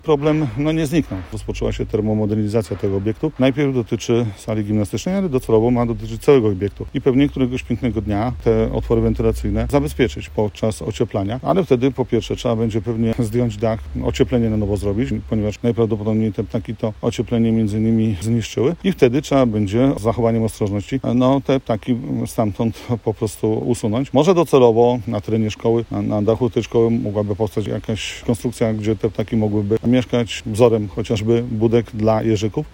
Radny Jacek Budziński, który jest także mieszkańcem osiedla Pomorskiego potwierdza, że problem istnieje. Jednak jego zdaniem zbliżająca się termomodernizacja budynku szkoły powinna zakończyć problem: https